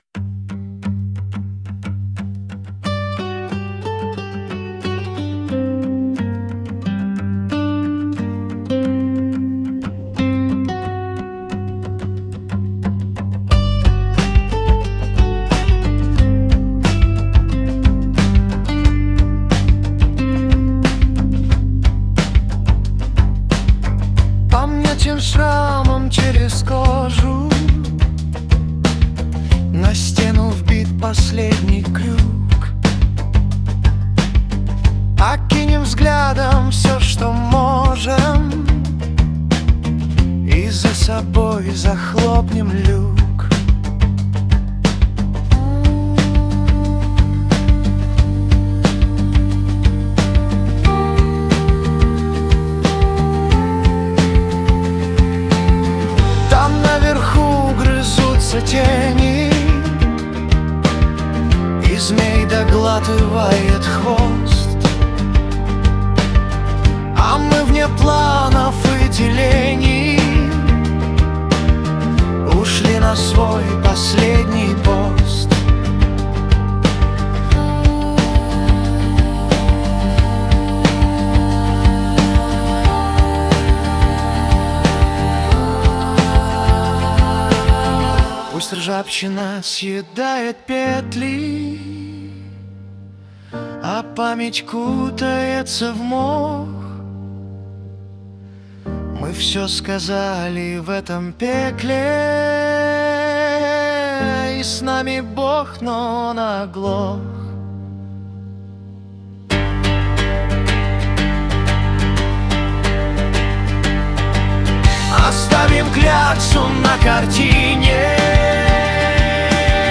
Музыкальный хостинг: /Рок